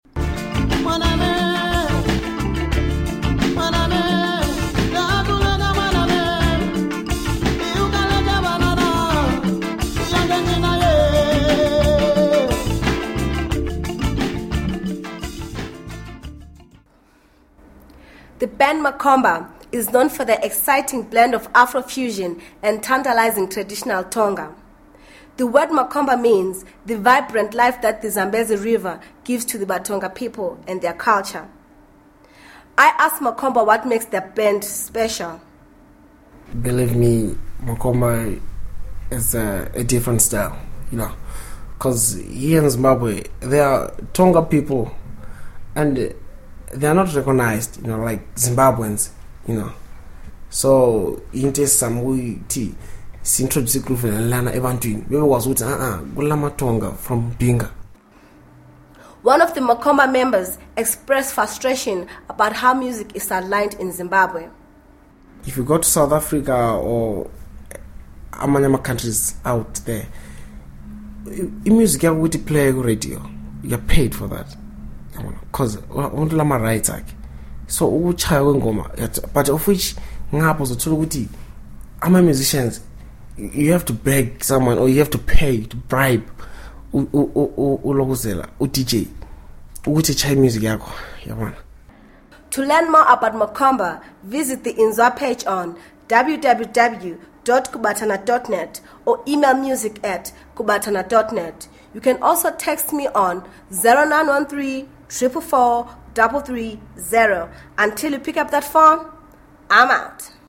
afro-fusion and traditional Tonga